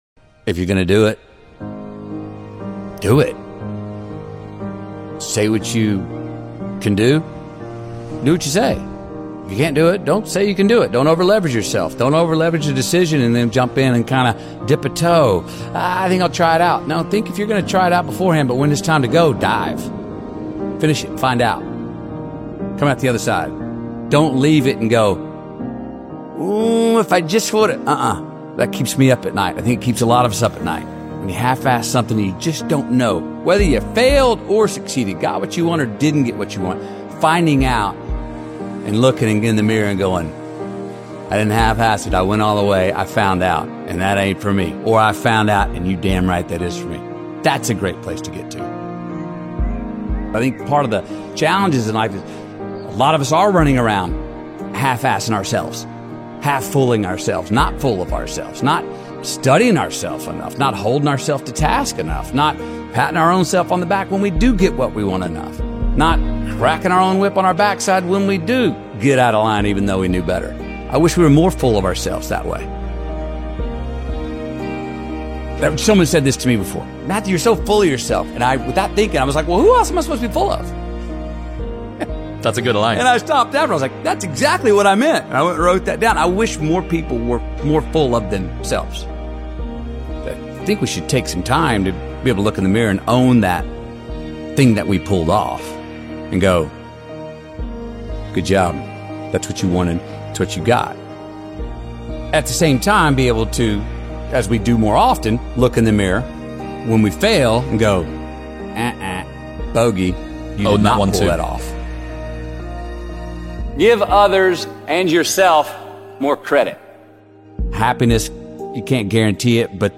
Speaker: Matthew McConaughey